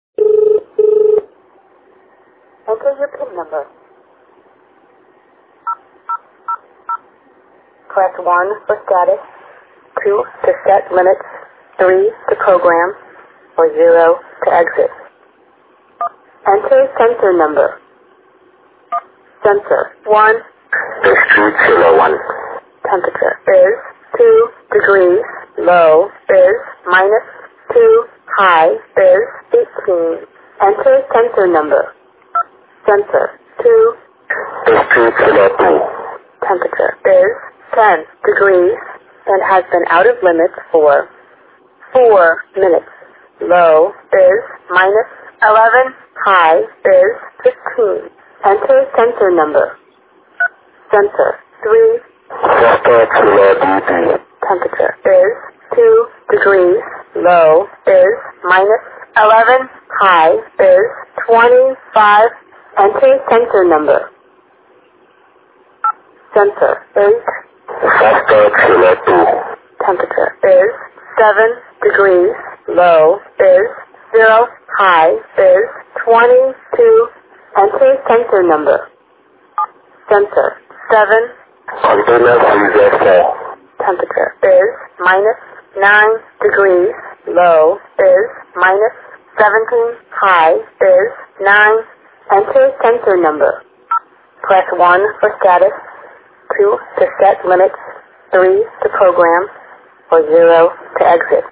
VM500-5 Voice Demonstration
Real Voice, Check the status of your equipment from anywhere